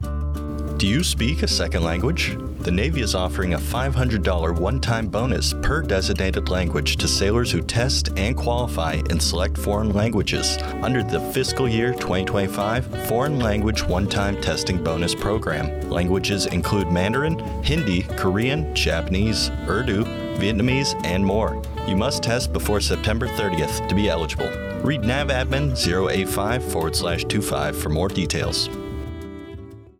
Radio Spot - Foreign Language One-Time Testing Bonus AFN Guantanamo Bay